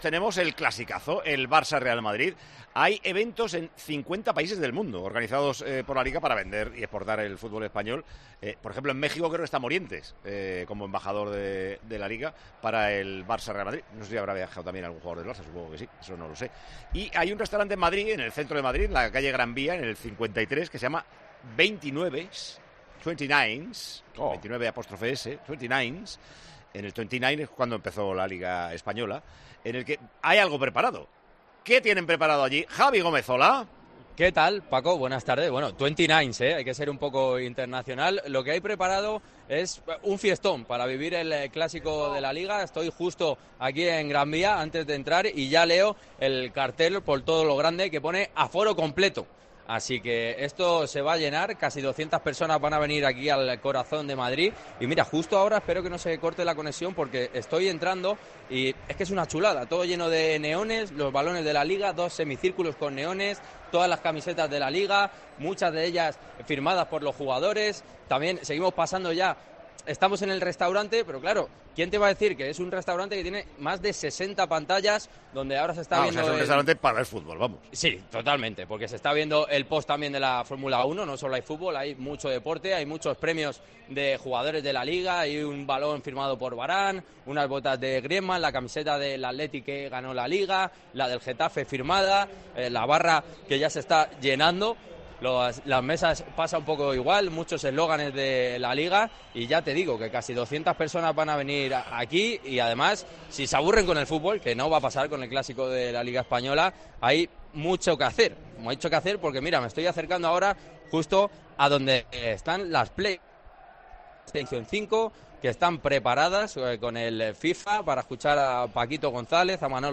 TwentyNines, el Sports bar de LaLiga, preparado para vivir ElClásico con el ambiente más futbolero